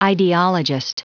Prononciation du mot ideologist en anglais (fichier audio)
Prononciation du mot : ideologist